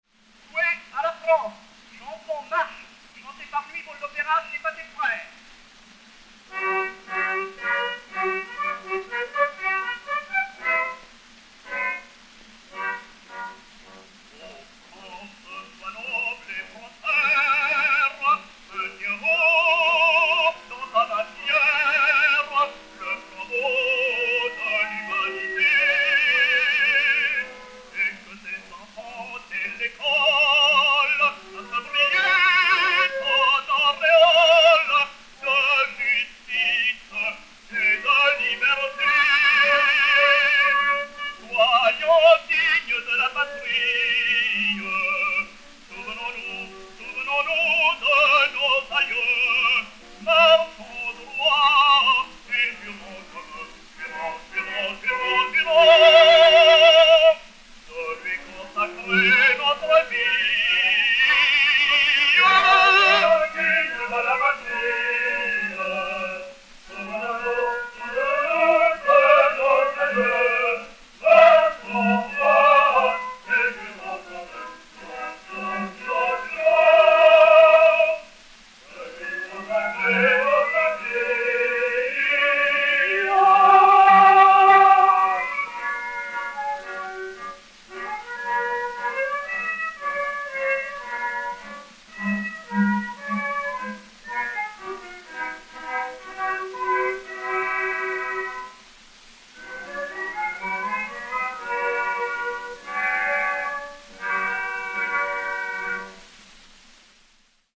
chanson de marche (par.
Orchestre
Pathé saphir 90 tours 4564 [a : 29 cm ; b : 21 cm], enr. à Paris en 1906/1907